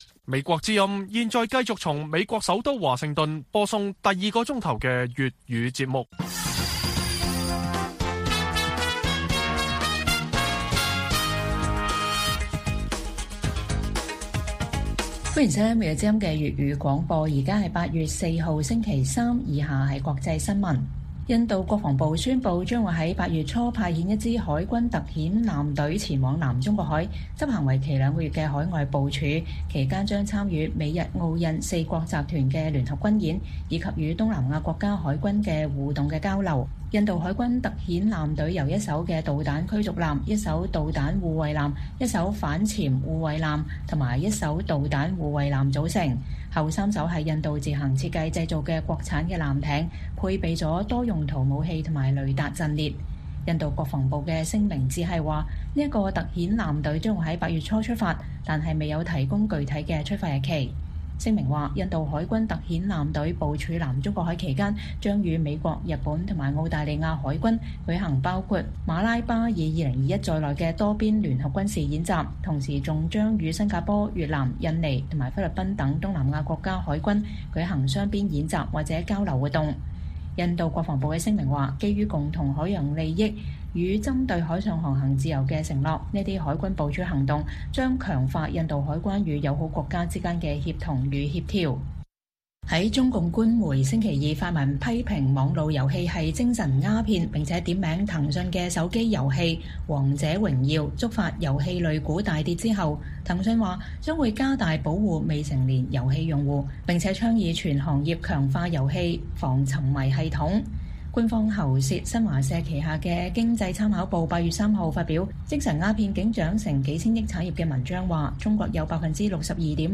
粵語新聞 晚上10-11點: 印度海軍特遣艦隊部署南中國海及西太平洋